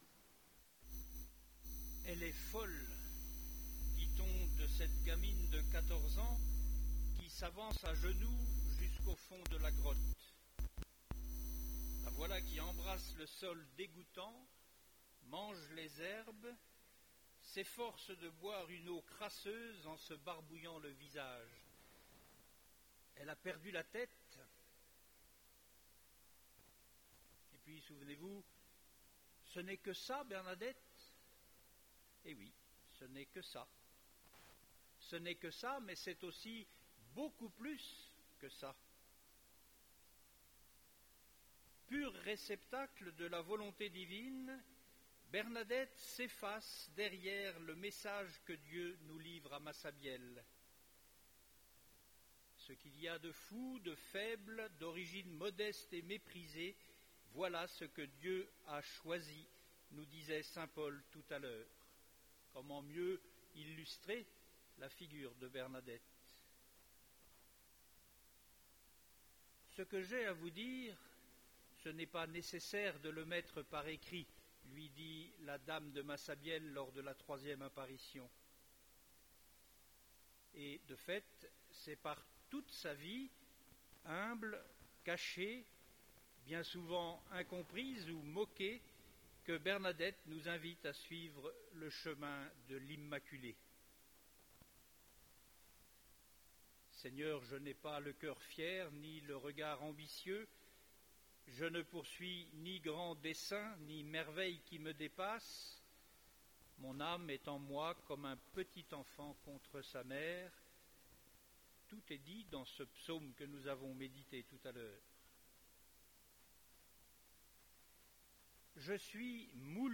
Messe d’installation des Reliques de Sainte Bernadette